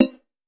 player-joined.wav